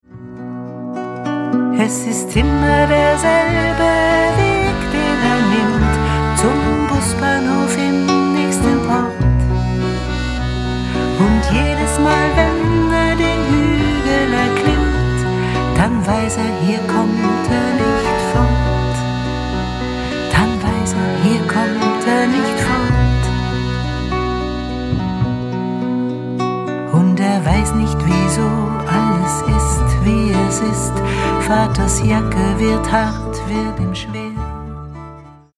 Gitarre, Bass, Ukulele, Gesang
Akkordeon
Klavier, Saxophone, Flöten